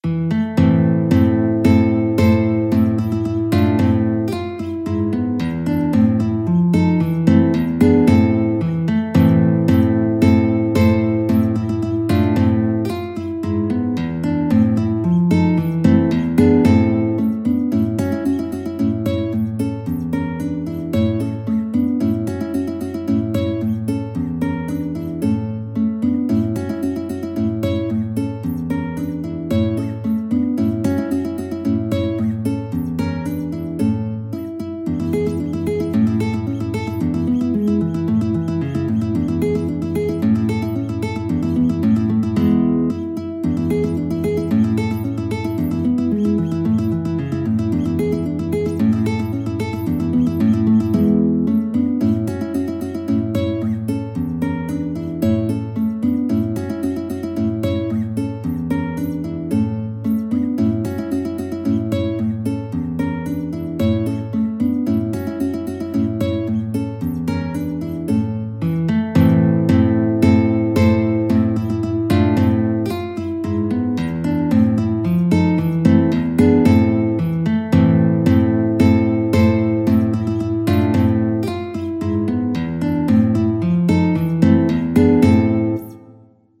A minor (Sounding Pitch) (View more A minor Music for Guitar )
2/4 (View more 2/4 Music)
Guitar  (View more Intermediate Guitar Music)
Classical (View more Classical Guitar Music)